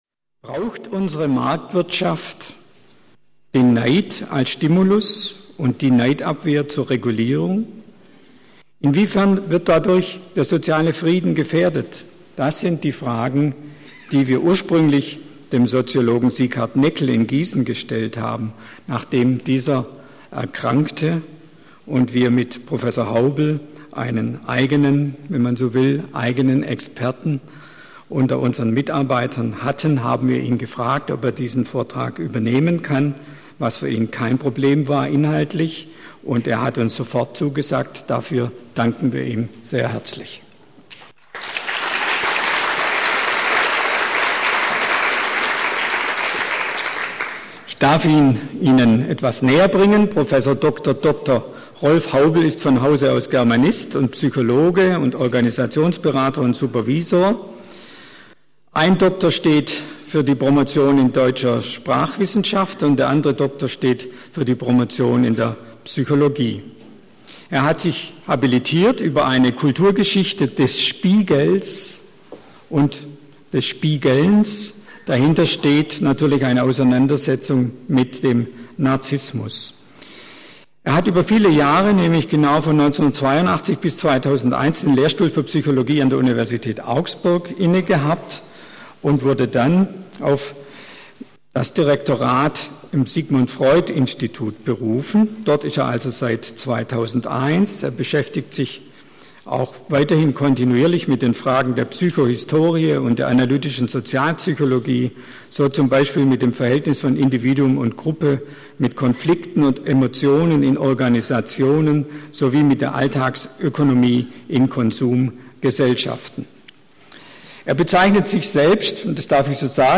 Sigmund-Freund-Institut Frankfurt Neidgesellschaft oder Mangel an sozialer Gerechtigkeit Abendvortrag im Rahmen der 57. Lindauer Psychotherapiewochen